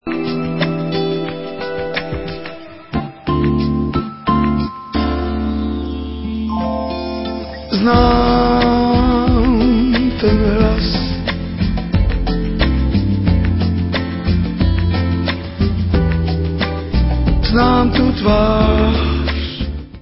sledovat novinky v oddělení Jazz